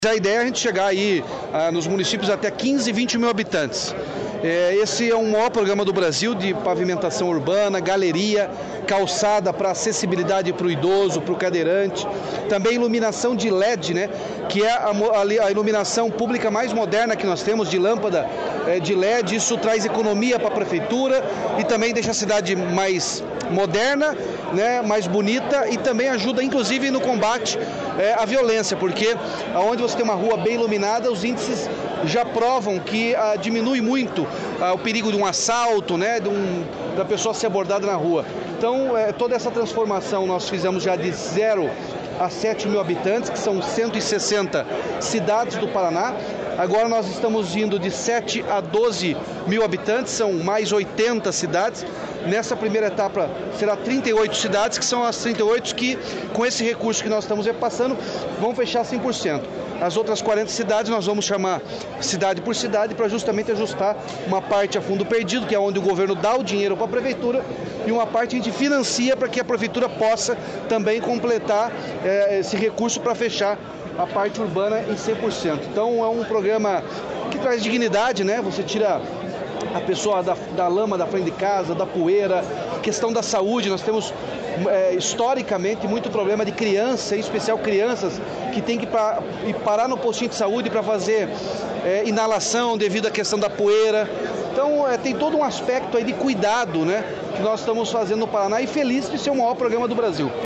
Sonora do governador Ratinho Junior sobre a nova fase do programa Asfalto Novo, Vida Nova